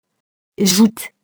joute [ʒut]